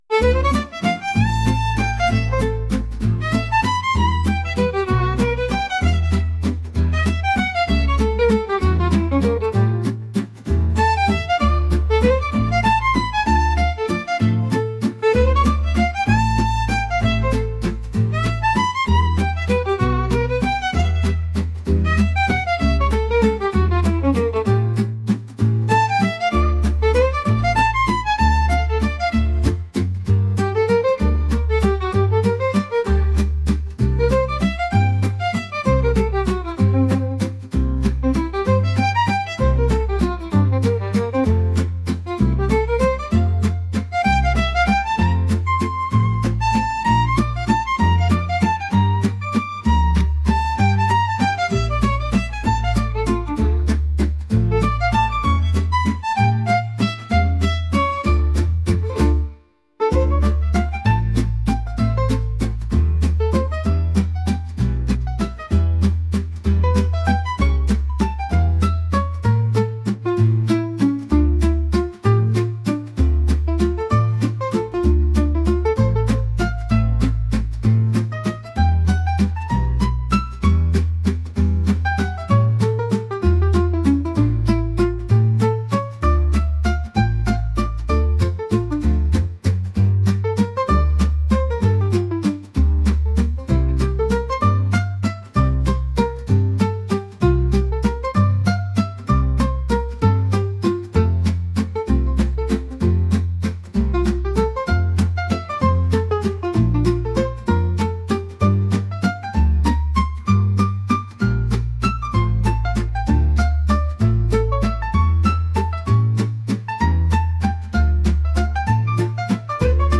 lively | jazz